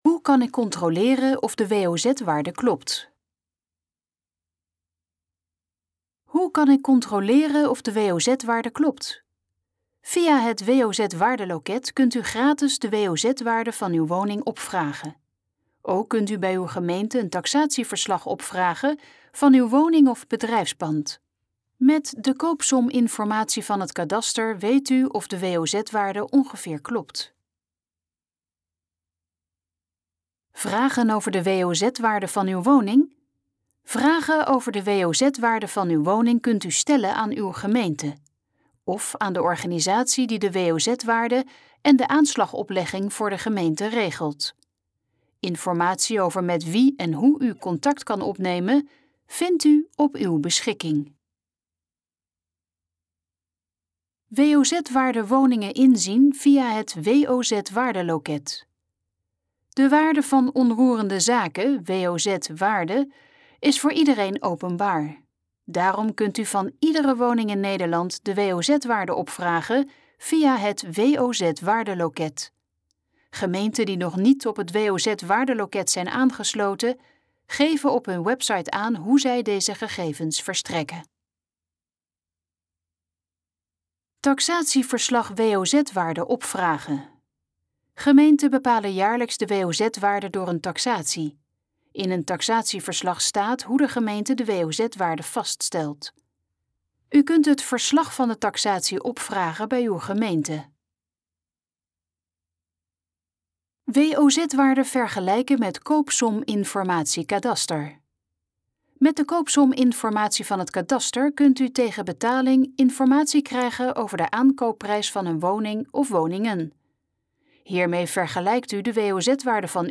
Dit geluidsfragment is de gesproken versie van de pagina Hoe kan ik controleren of de WOZ-waarde klopt?